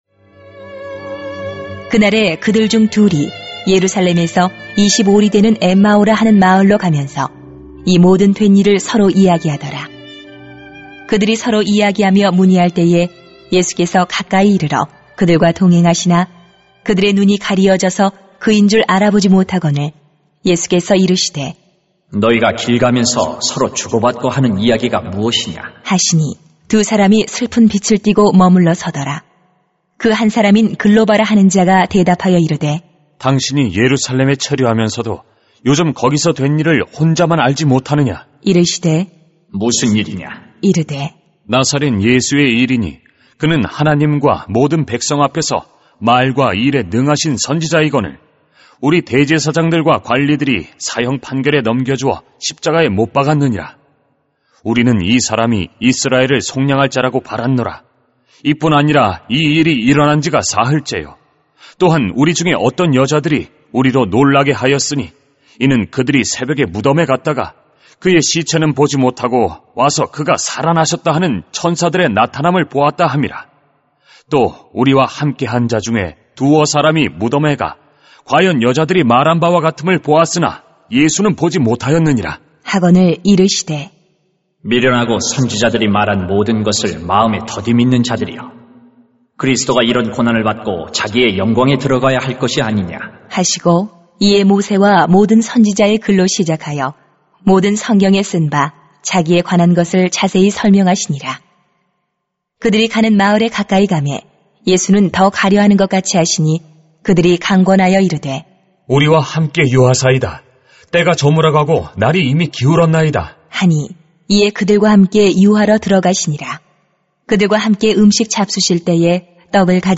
[눅 24:13-35] 회심한 두 제자의 이야기 > 새벽기도회 | 전주제자교회